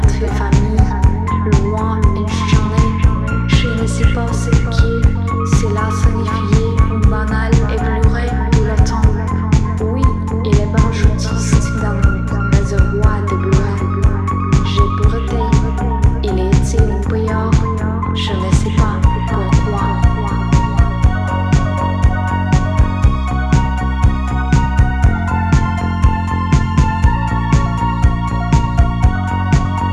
French Pop
2016-03-26 Жанр: Поп музыка Длительность